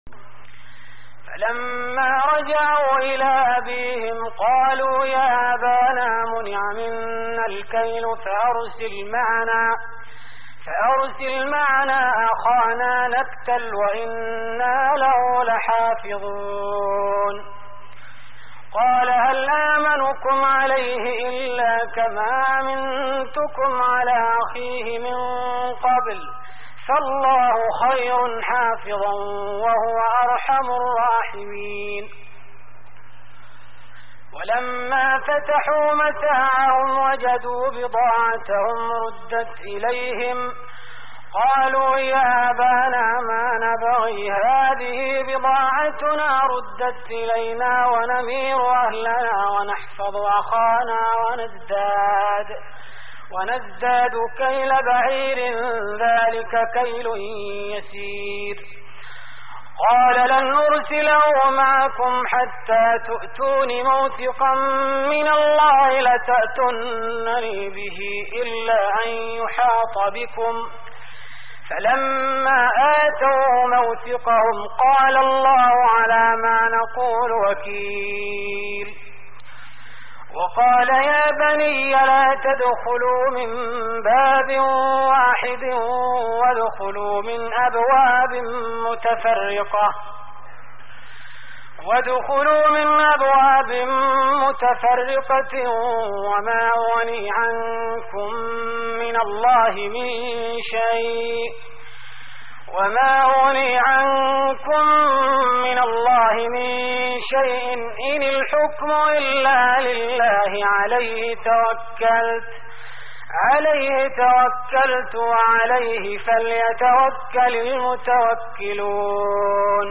تهجد رمضان 1416هـ من سورتي يوسف (63-111) و الرعد (1-18) Tahajjud Ramadan 1416H from Surah Yusuf and Ar-Ra'd > تراويح الحرم النبوي عام 1416 🕌 > التراويح - تلاوات الحرمين